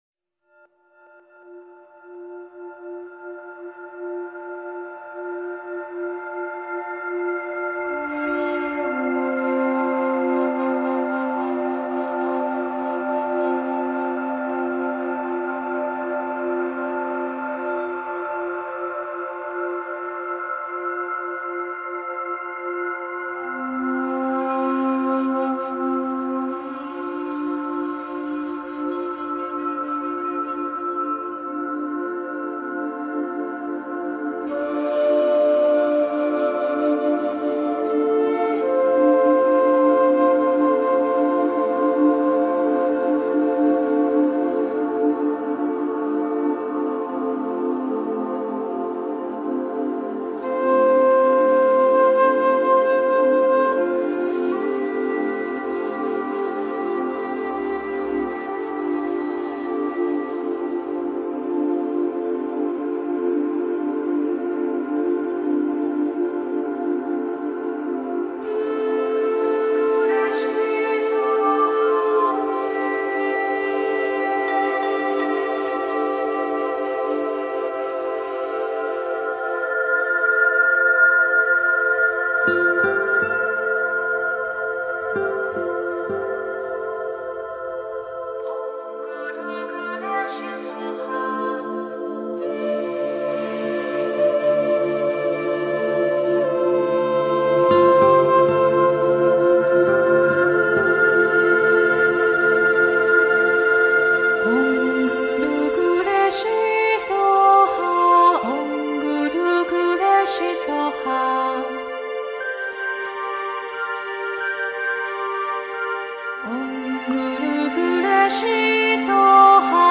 标签: 佛音 冥想 佛教音乐